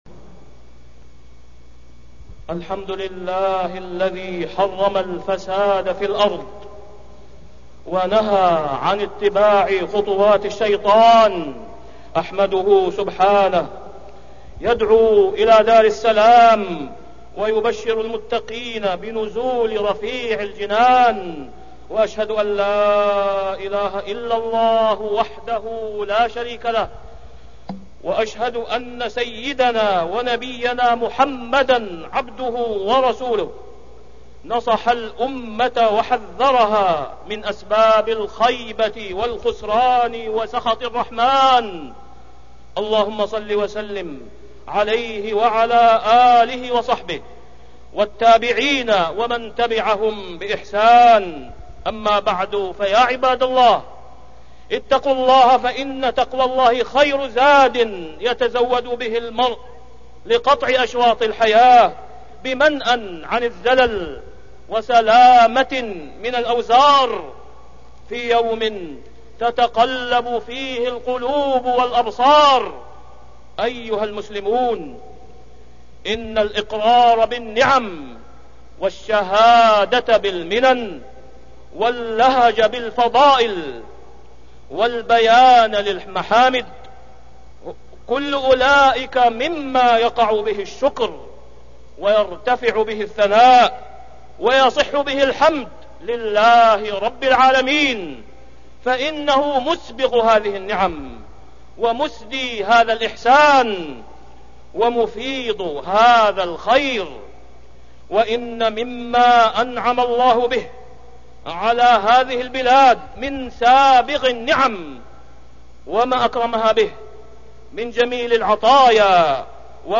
التصنيف: خطب الجمعة